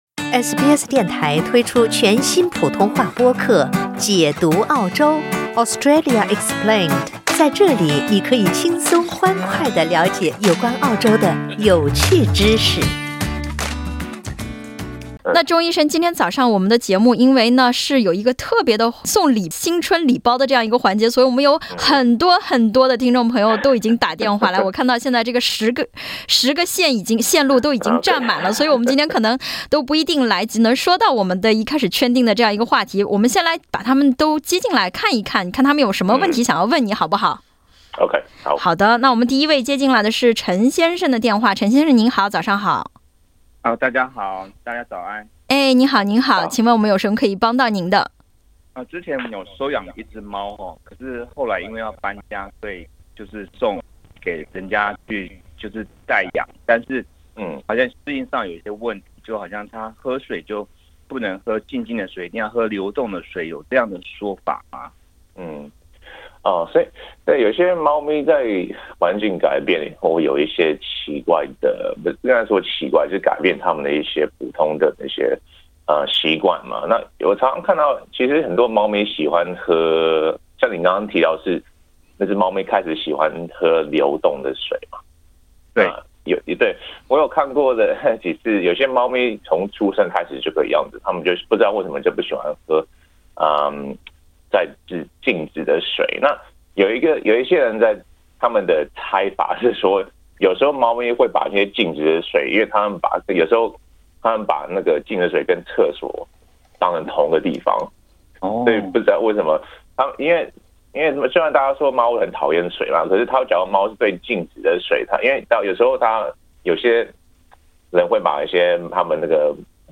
點擊封面圖片收聽熱線寀訪。